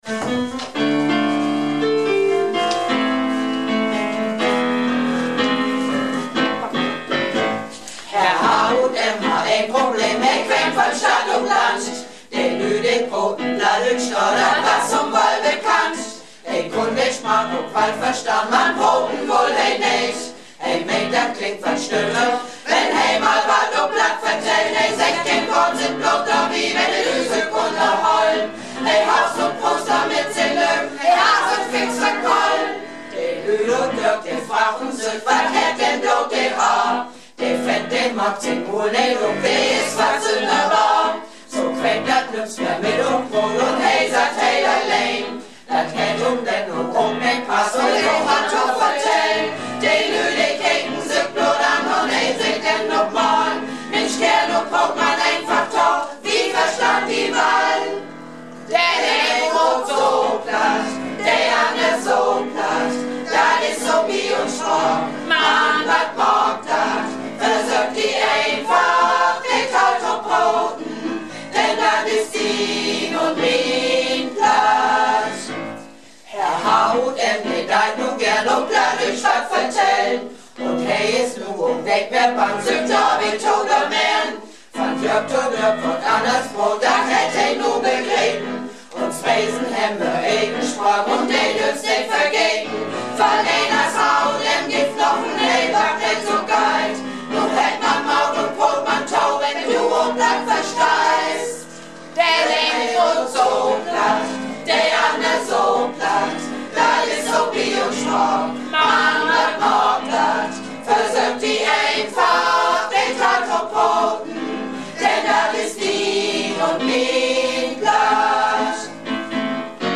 Malle Diven - Probe am 27.01.15